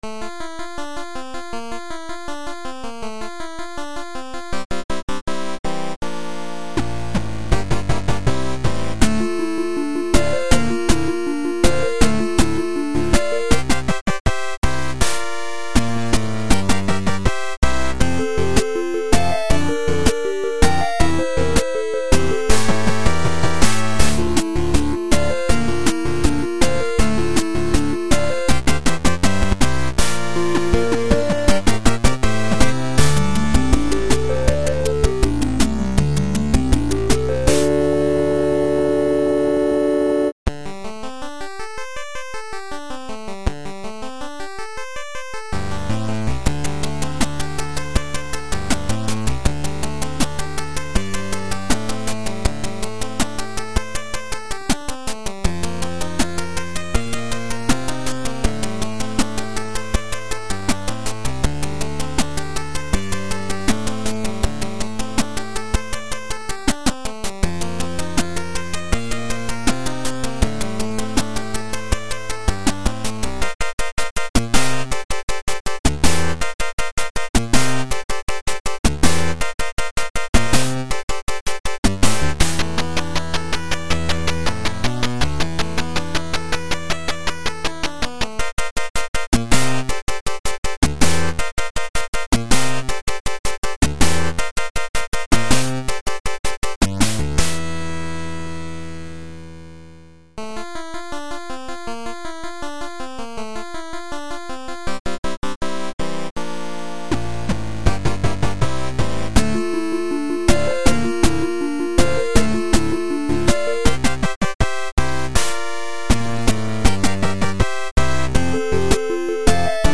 MIDI version